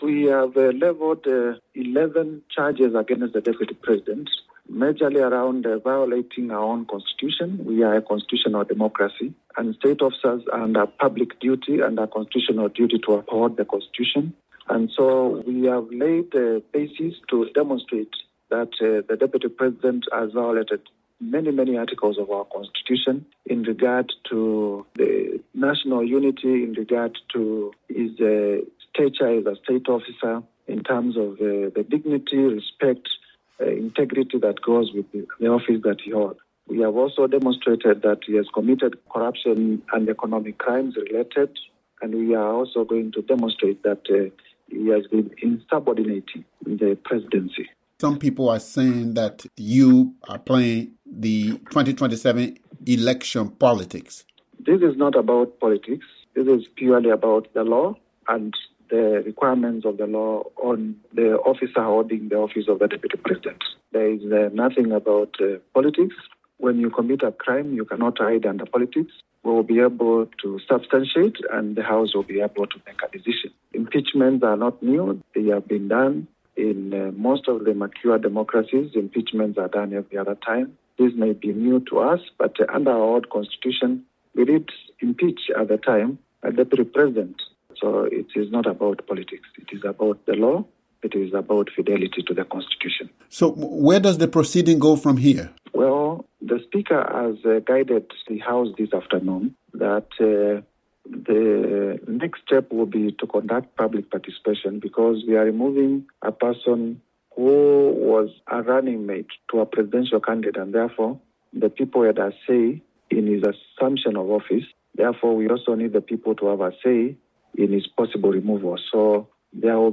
The motion to impeach was made by Honorable Mwengi Mutuse (moo-TOO-she), a member of Kenya’s ruling United Democratic Alliance (UDA). He explains the charges.